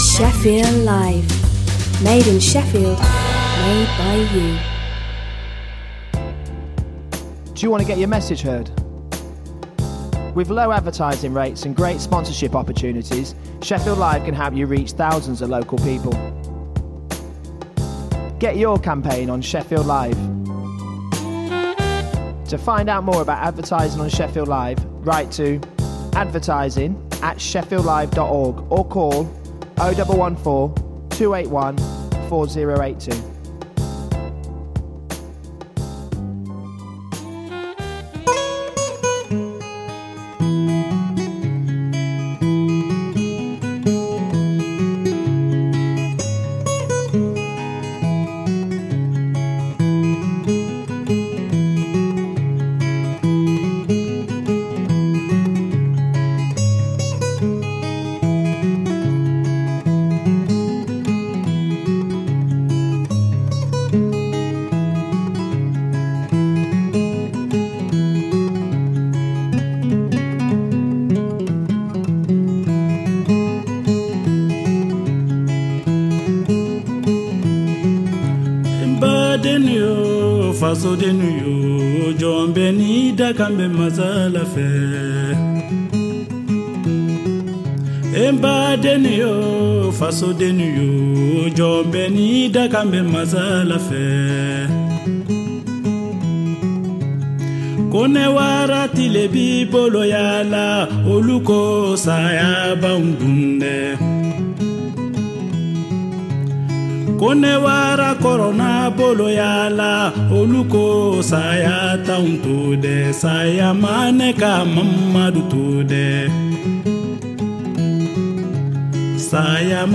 Business news, debate and interviews for anyone interested in growing or starting a business.